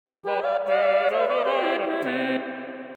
Blob Opera